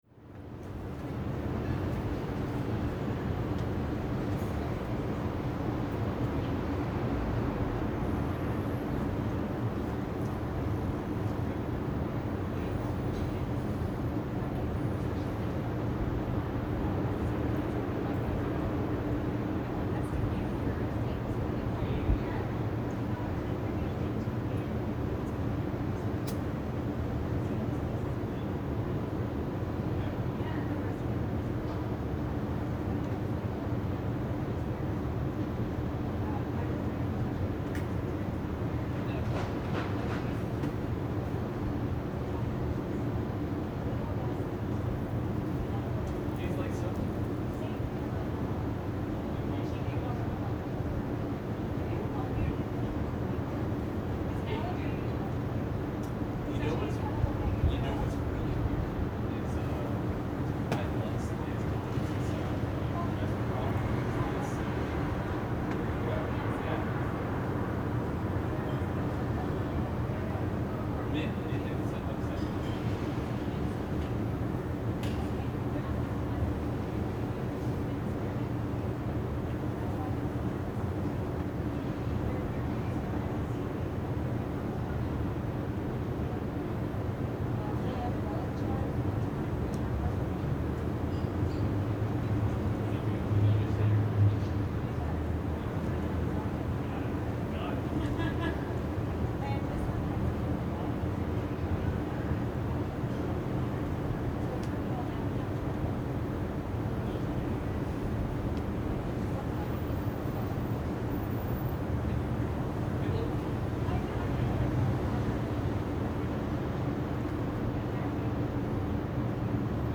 In this recording we hear some social interactions, a bit of car troubles, a sweet motorcycle, and the constant hum of generators and A/C units.